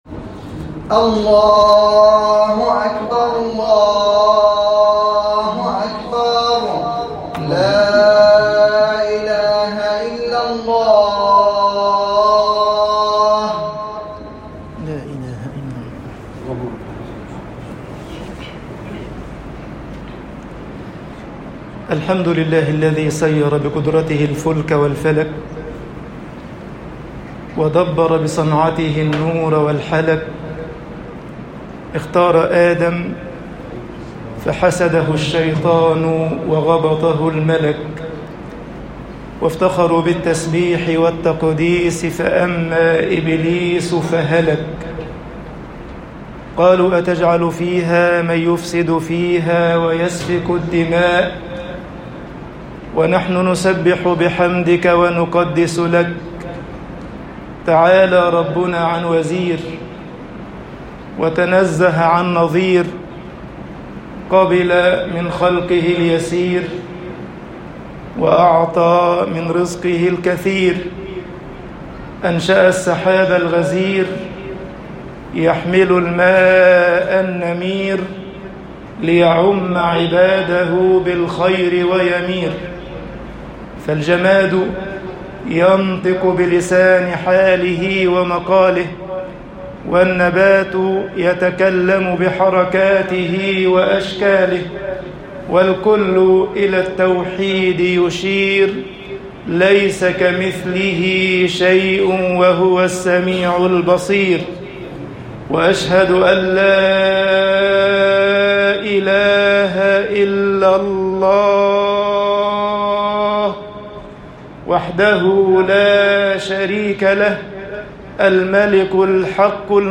خطب الجمعة - مصر الْهِدَايَةُ فِي الاتِّبَاعِ وَالطَّاعَةِ طباعة البريد الإلكتروني التفاصيل كتب بواسطة